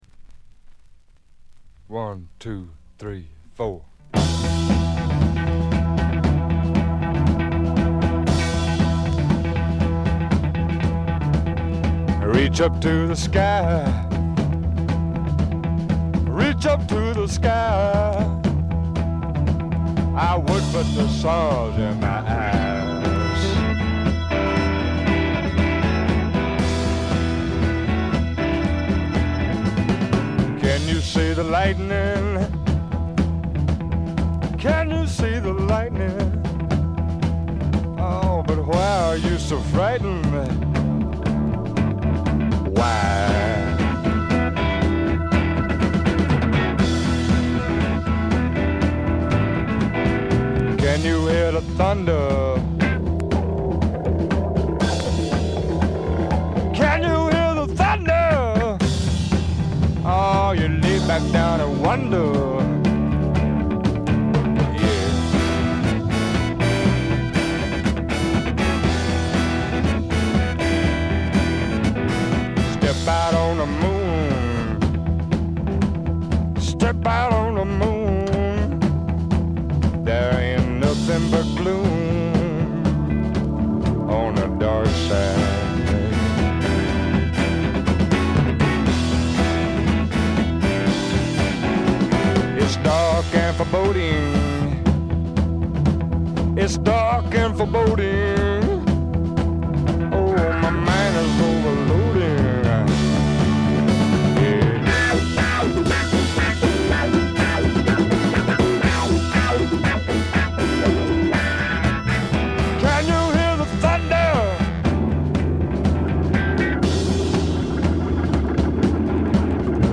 軽微なバックグラウンドノイズ、散発的なプツ音が数か所。
ハードなファンキースワンプから甘いバラードまで、メンフィス録音スワンプ基本中の基本ですね。
試聴曲は現品からの取り込み音源です。
guitar, harmonica